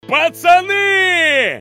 мужской голос
голосовые
из мультсериала